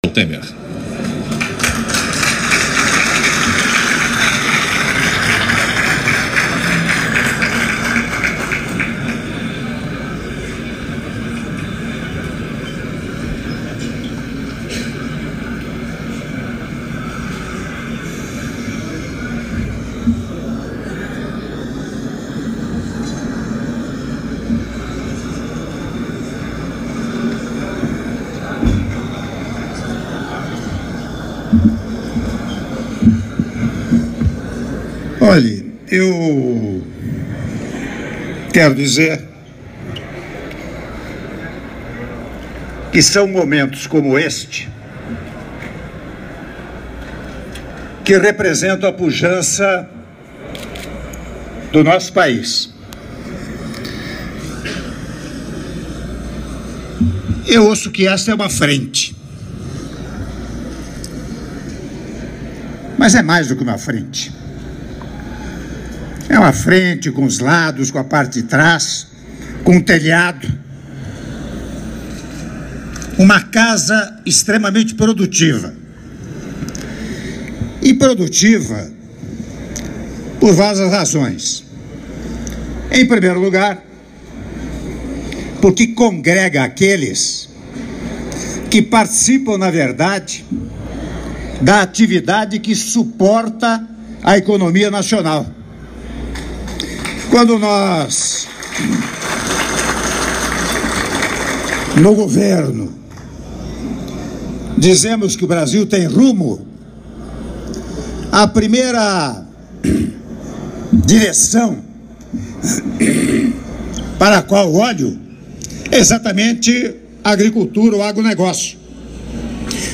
Áudio do discurso do Presidente da República, Michel Temer, durante cerimônia de posse do novo Presidente da Frente Parlamentar da Agropecuária - Brasília/DF (06min42s) — Biblioteca